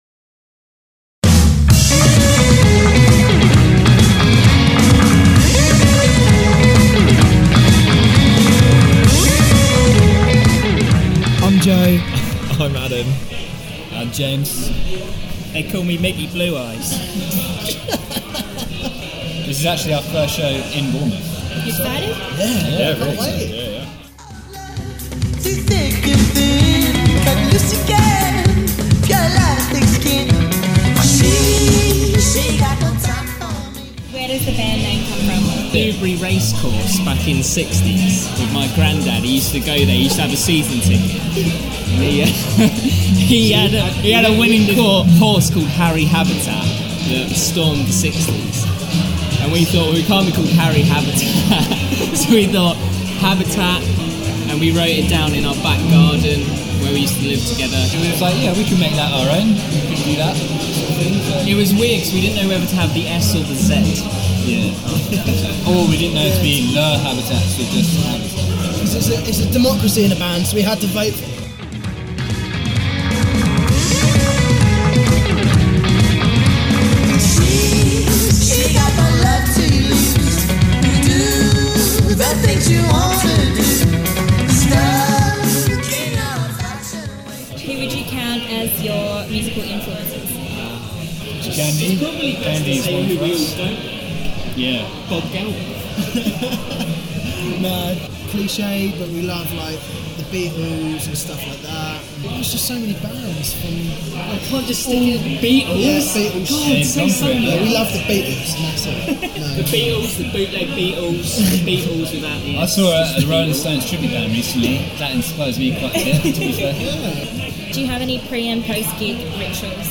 Band Interview – Habitats